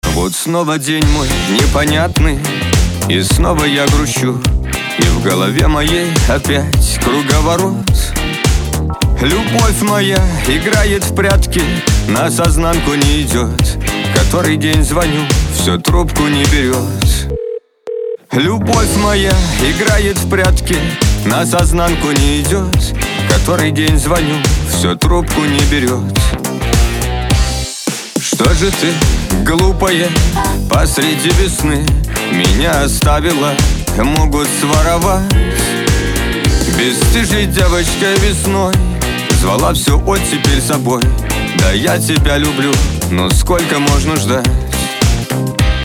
• Качество: 256, Stereo
мужской вокал
русский шансон